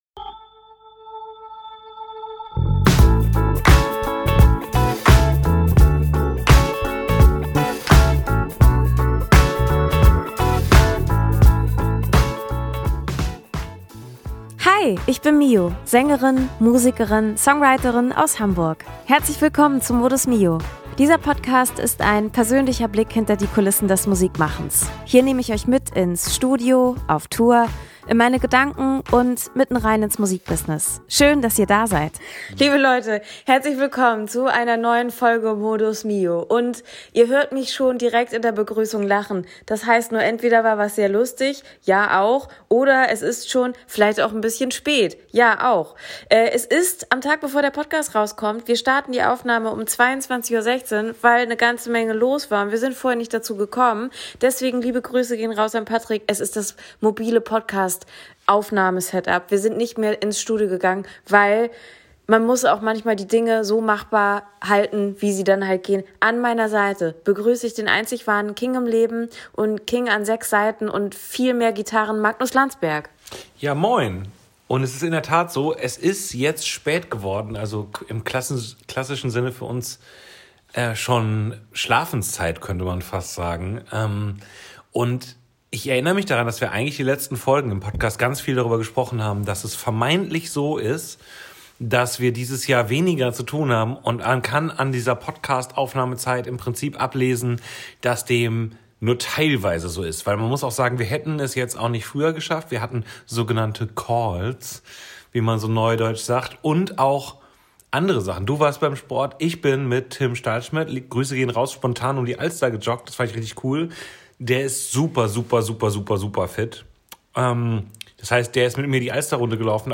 Machen wir's kurz: Wir hatten ne wirklich schöne Folge, die wir am Vorabend um knapp 23h(!) für euch aufgenommen haben - und die Technik hat leider gestreikt und mittendrin fehlen so bummelig 40 Min Material.